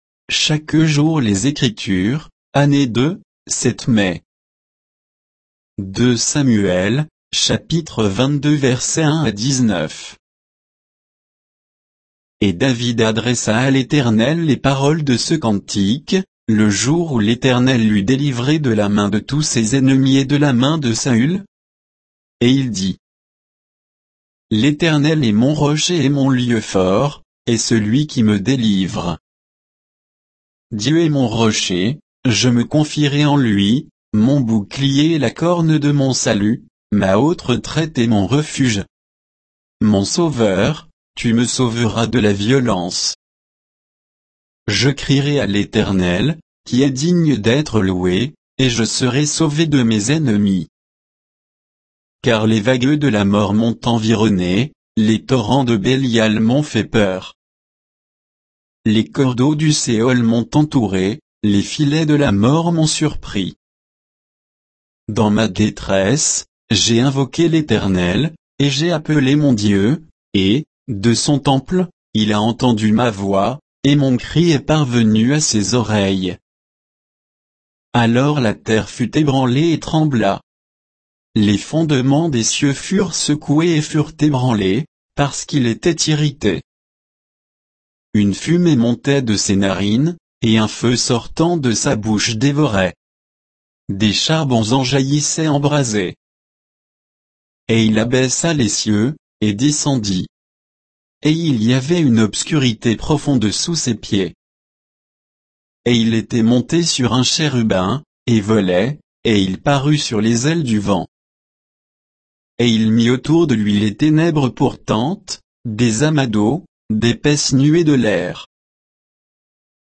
Méditation quoditienne de Chaque jour les Écritures sur 2 Samuel 22